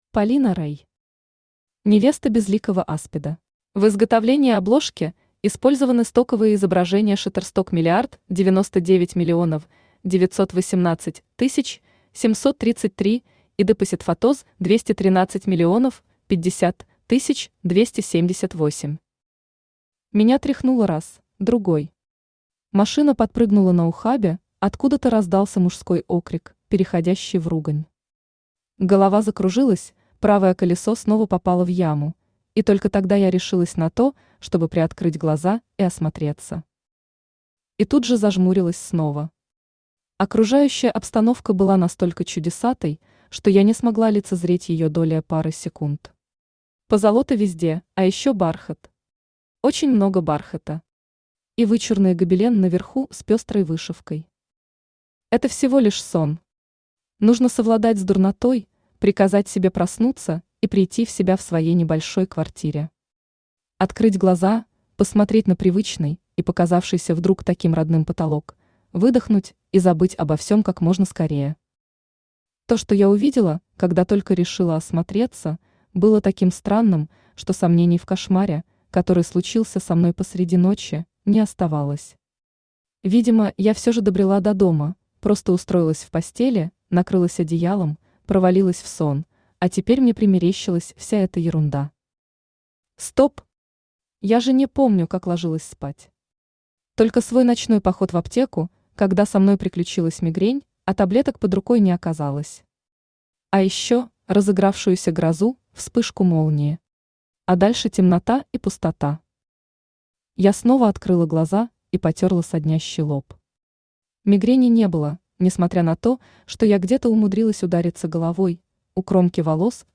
Аудиокнига Невеста безликого Аспида | Библиотека аудиокниг
Aудиокнига Невеста безликого Аспида Автор Полина Рей Читает аудиокнигу Авточтец ЛитРес.